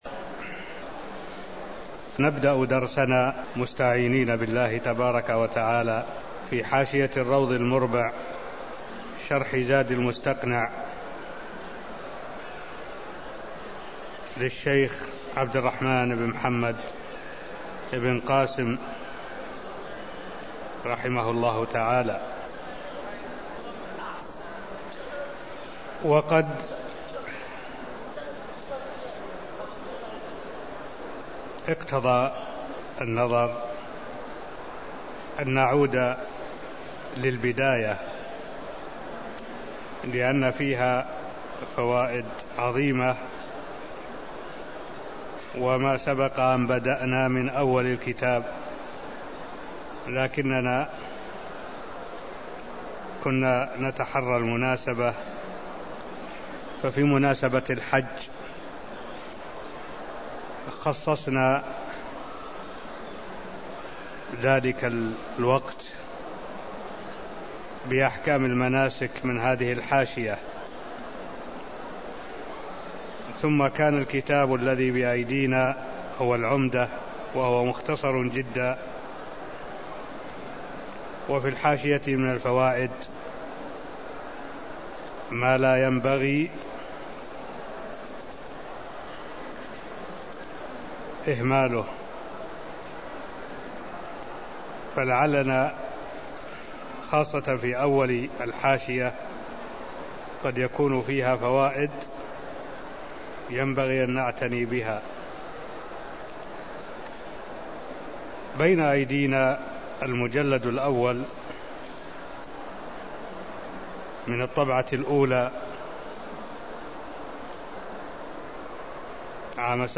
المكان: المسجد النبوي الشيخ: معالي الشيخ الدكتور صالح بن عبد الله العبود معالي الشيخ الدكتور صالح بن عبد الله العبود أصول وقواعد وتنبيهات (0001)